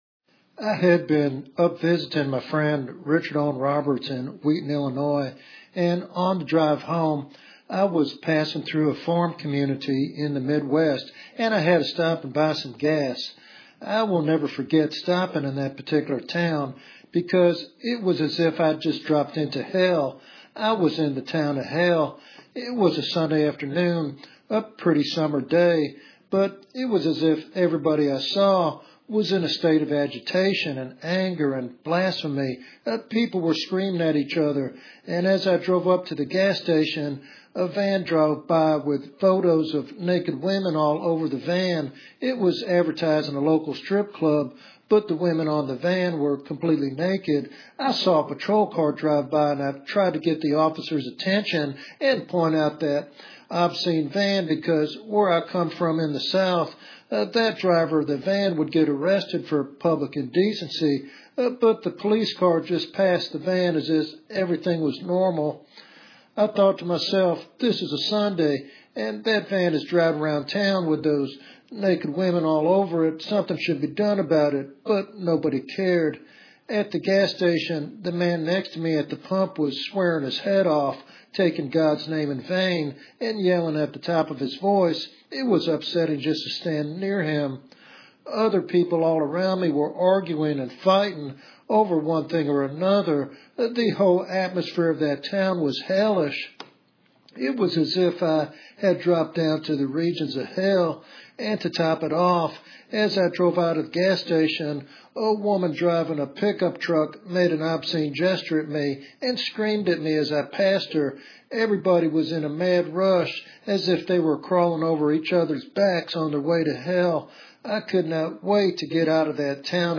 This evangelistic sermon calls listeners to confront spiritual decay with courage and faith, trusting in God's power to transform even the darkest places.